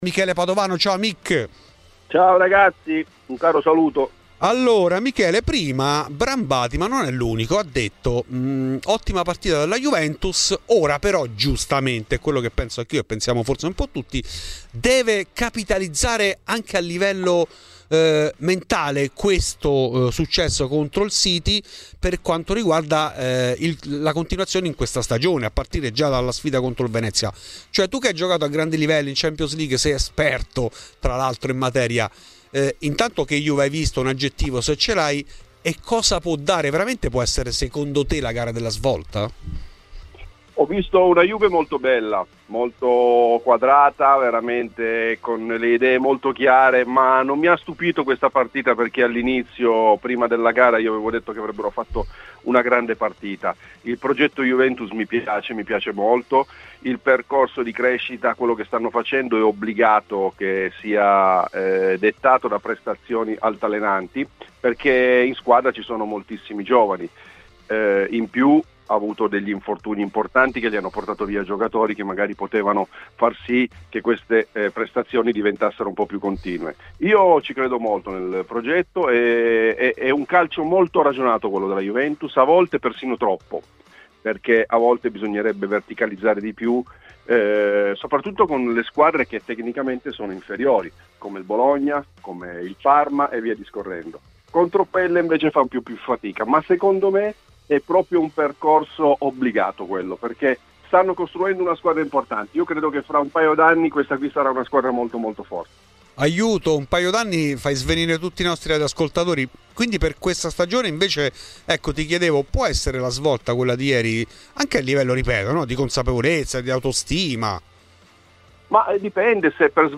Questi ed altri temi sono contenuti nell'intervento in ESCLUSIVA a Fuori di Juve di Michele Padovano .